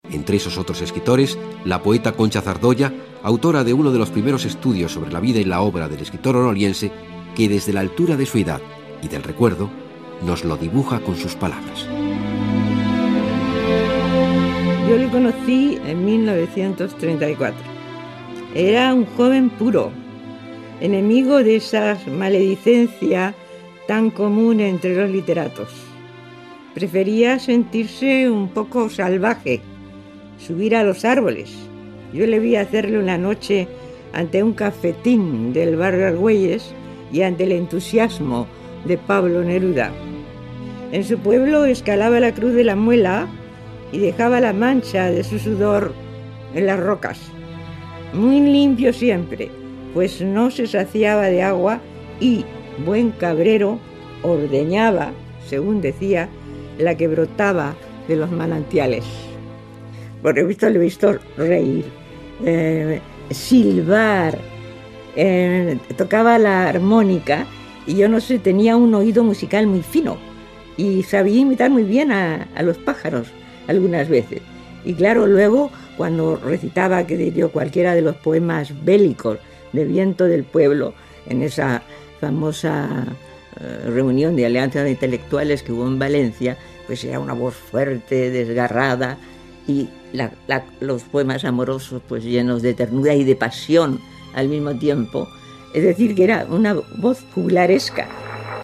L'escrpitora Concha Zardoya explica com era el poeta Miguel Hernández
Divulgació